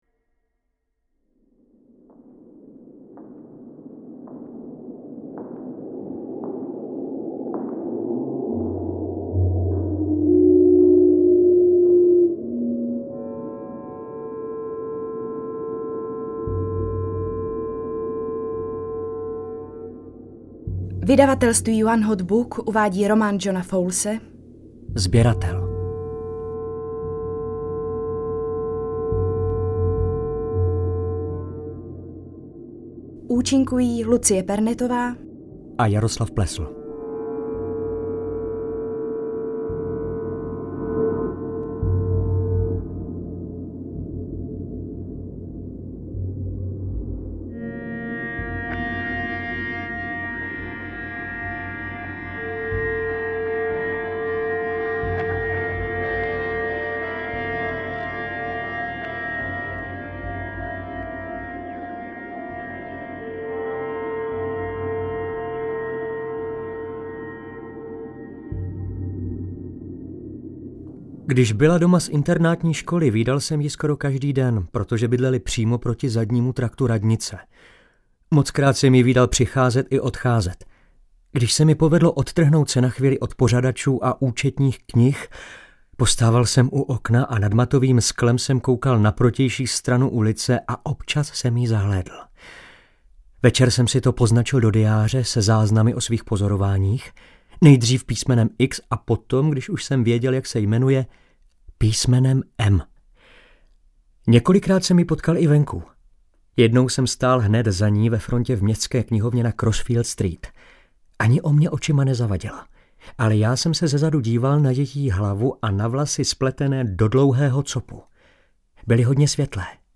Audiokniha roku 2015